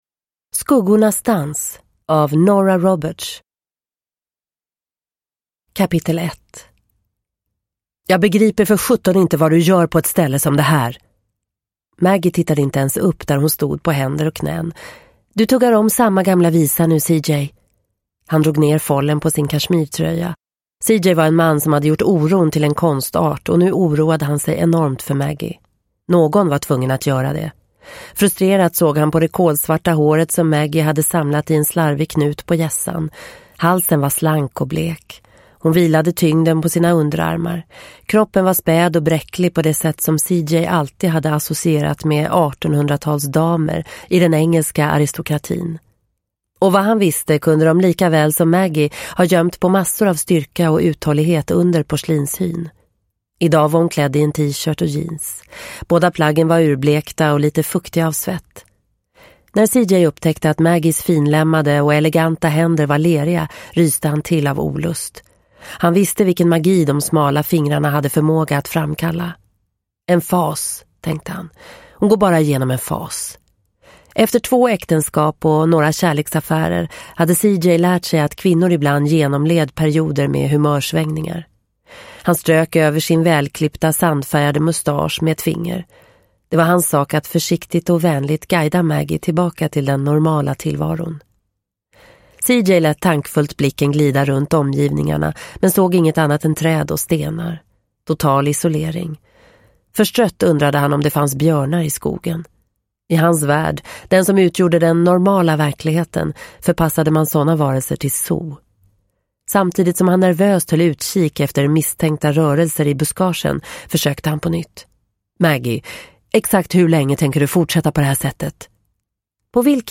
Skuggornas dans – Ljudbok – Laddas ner